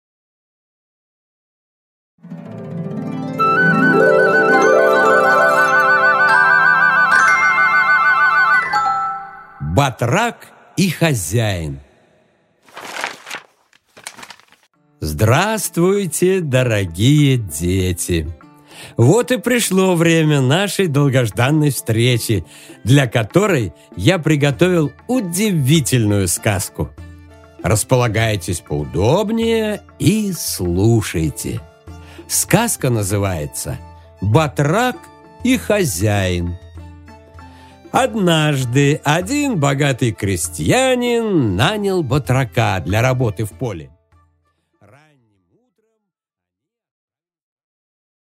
Аудиокнига Батрак и хозяин | Библиотека аудиокниг